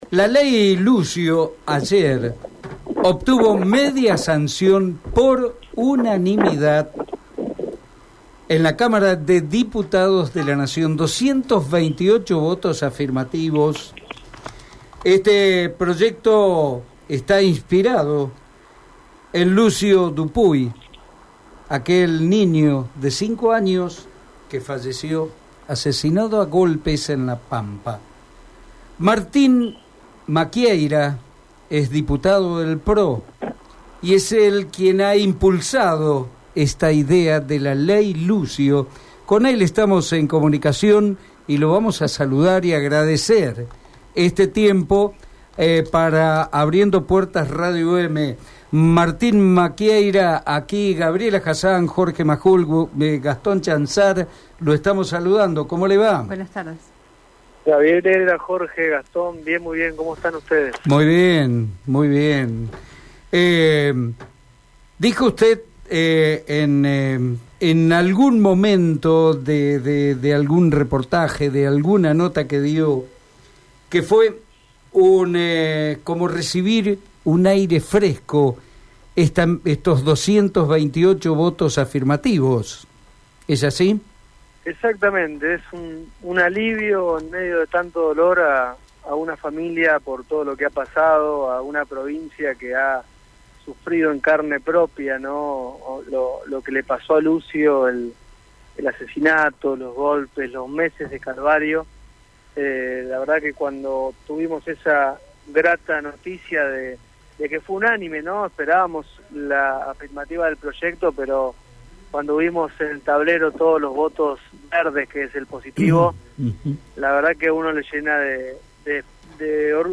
Escucha lo que dijo Martín Maquieyra en Radio EME: